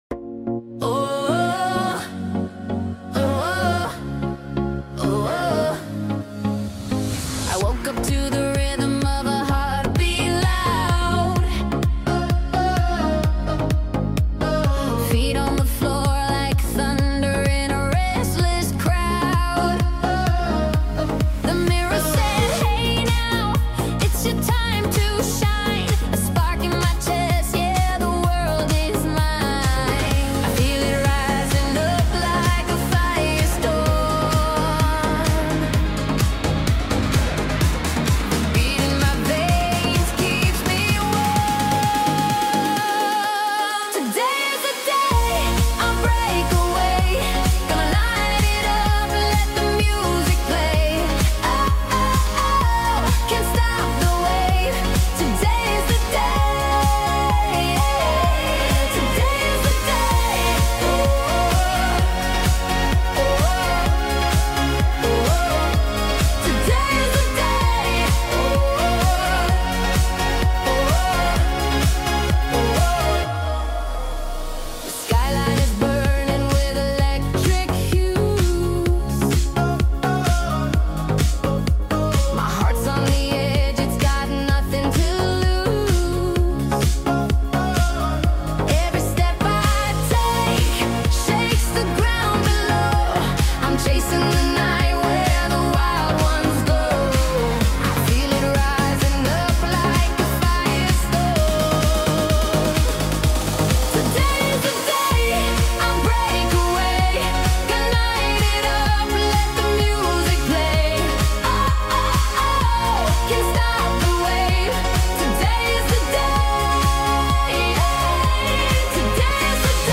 New Dance EDM Music 2025